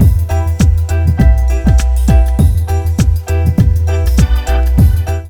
RAGGALOOP1-R.wav